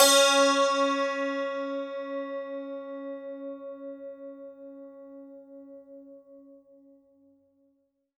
52-str05-sant-c#3.wav